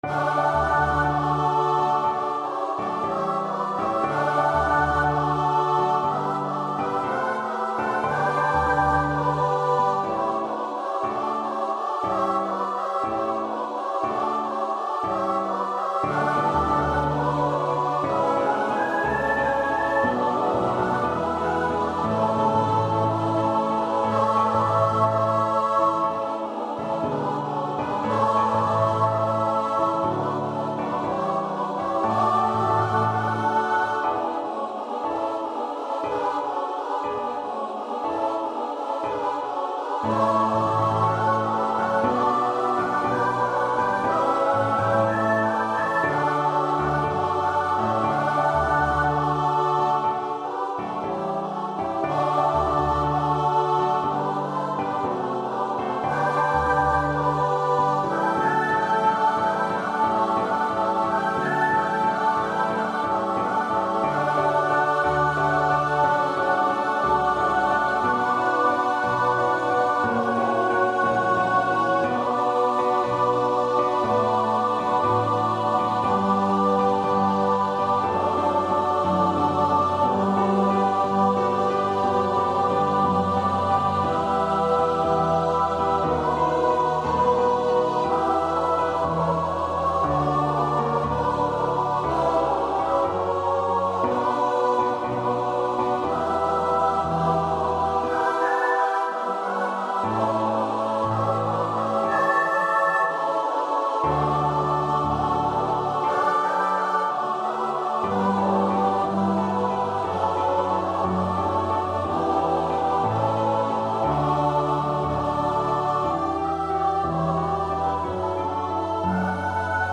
Free Sheet music for Choir (SSAATB)
Choir  (View more Intermediate Choir Music)
Classical (View more Classical Choir Music)